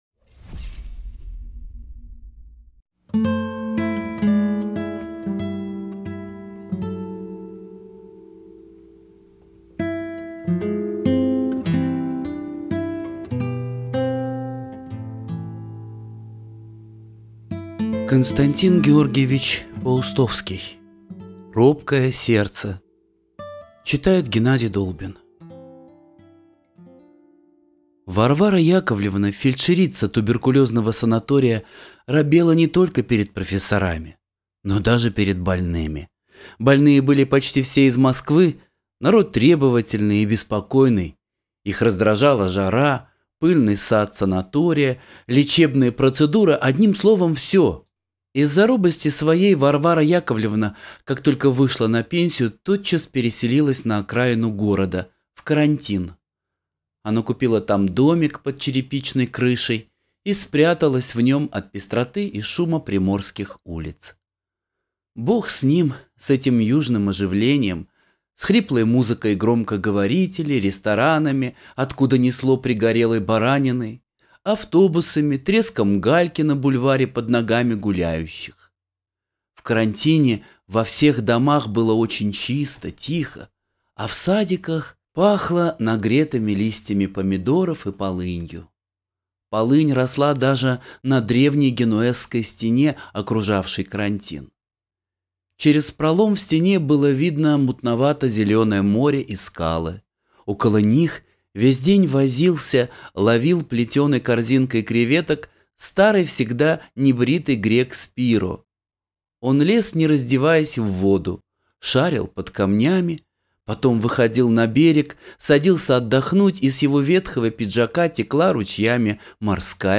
Робкое сердце - аудио рассказ Паустовского - слушать скачать